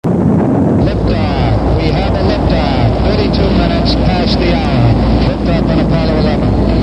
7.nasa liftoff
nasa_liftoff.mp3